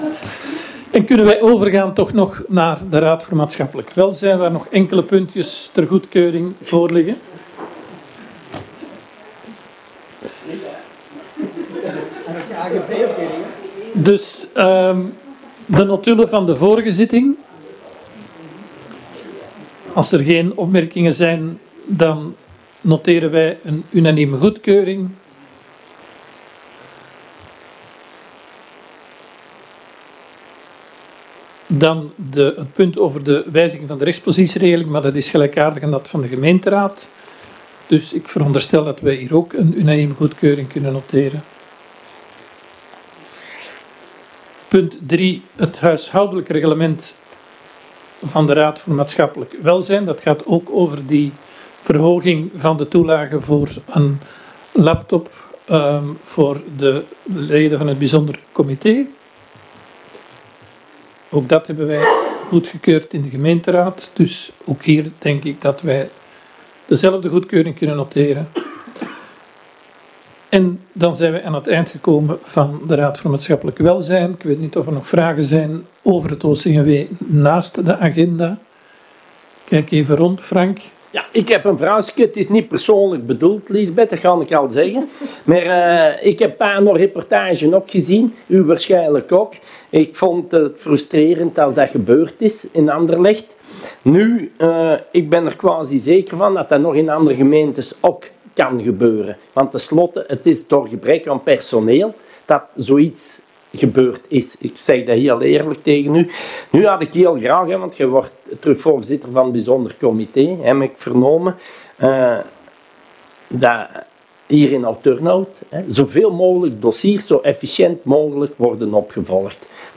Raad voor maatschappelijk welzijn 21/11/2024